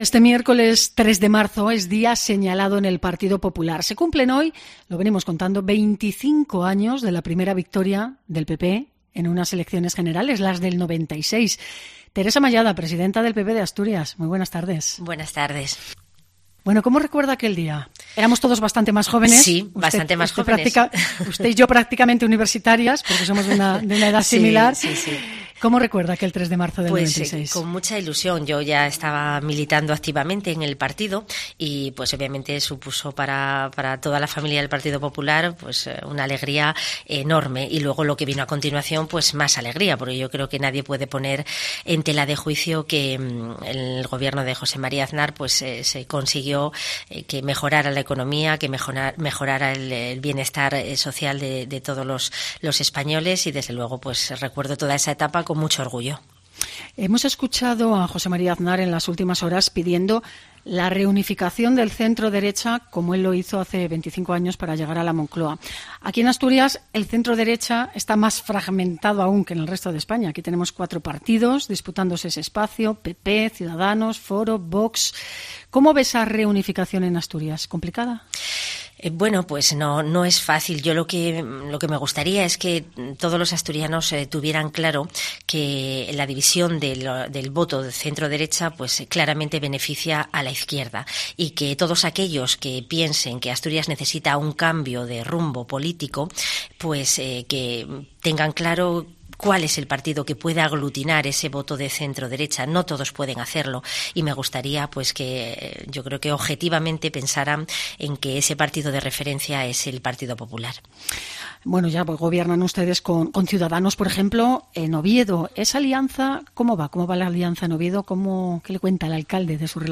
Entrevista a Teresa Mallada